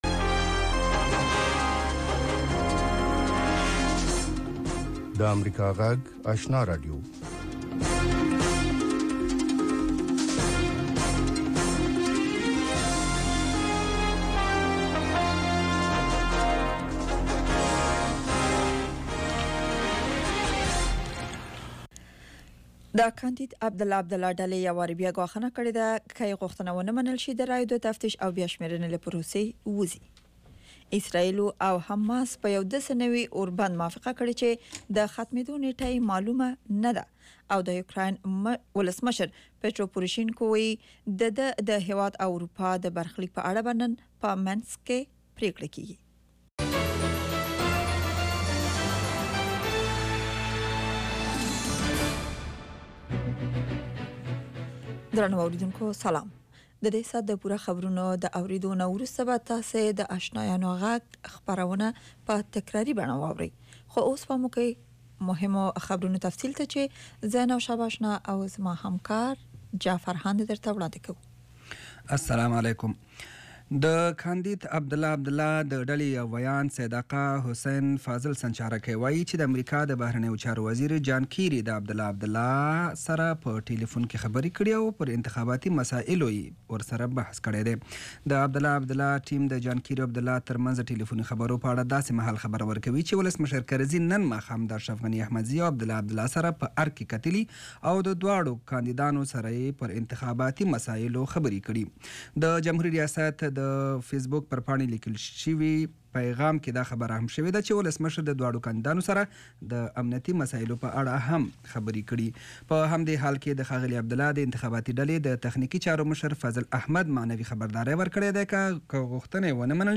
یو ساعته پروگرام: تازه خبرونه، او د نن شپې تېر شوي پروگرامونه په ثبت شوي بڼه، هنري، علمي او ادبي مسایلو په اړه د شعر، ادب او بیلا بیلو هنرونو له وتلو څیرو سره.